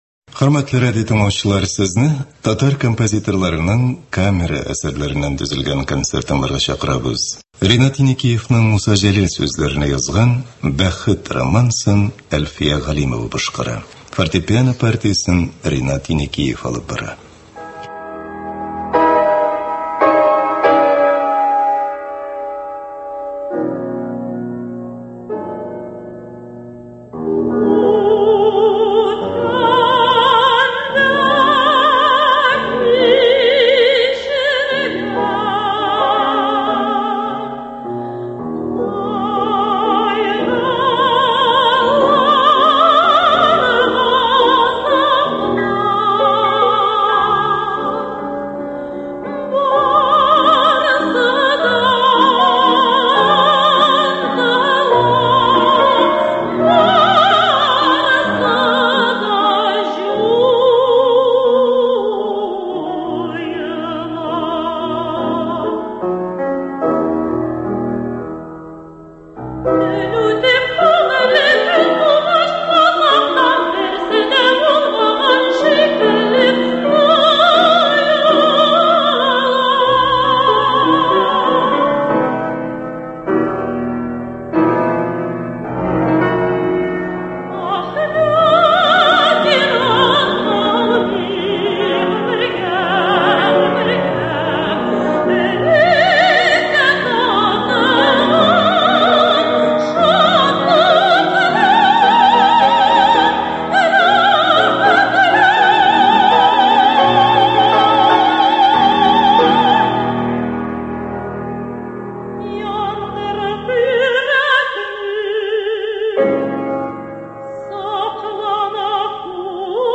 Камера музыкасы концерты.